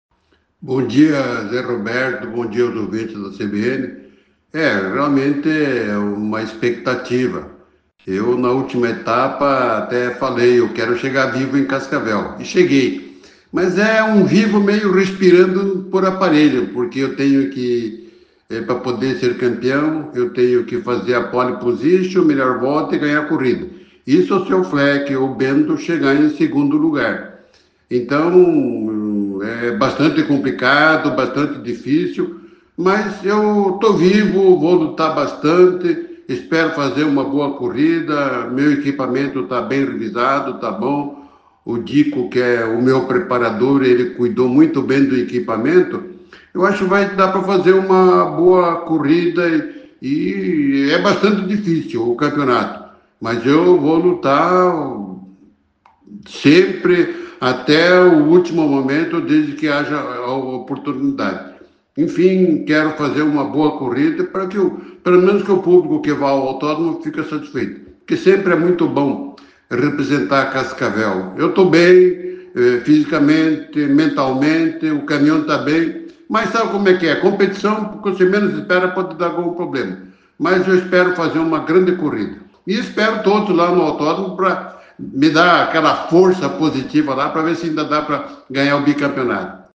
Em entrevista à CBN Cascavel nesta sexta-feira (29) Pedro Muffato, piloto da Fórmula Truck, destacou a ultima prova da temporada 2024, programada para domingo (01) no Autódromo Zilmar Beux.